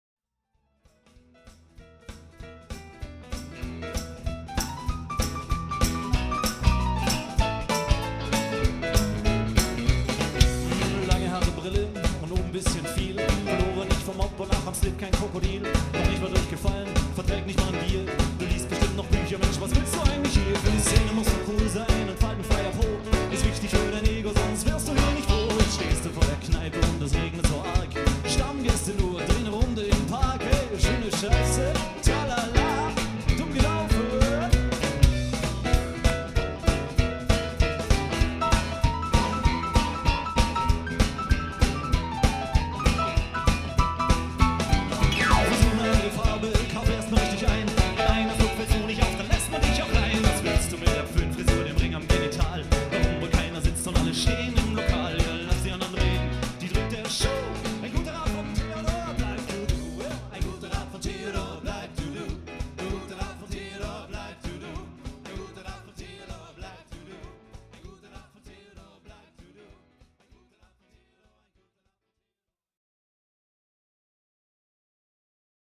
• Livemitschnitte 1999-2001
Pförring, OpenAir 2001 (3:39)